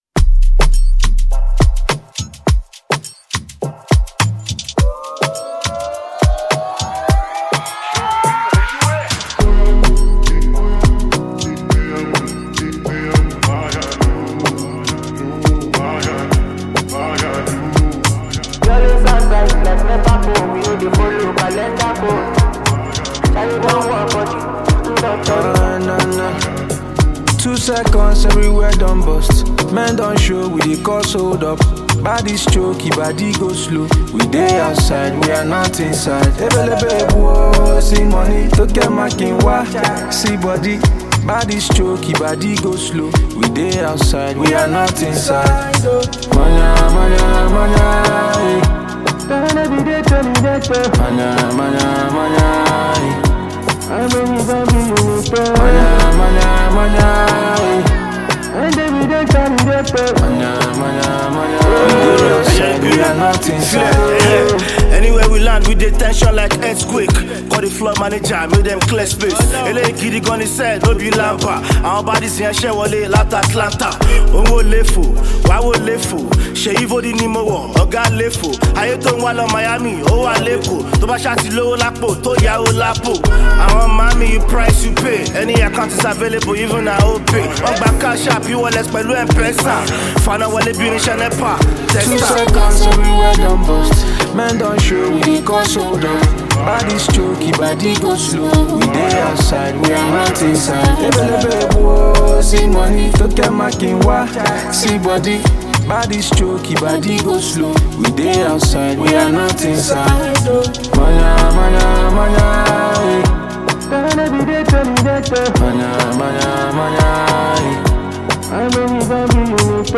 Well renowned Nigerian rapper and songwriter
gbedu song